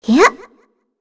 Shea-Vox_Jump_kr.wav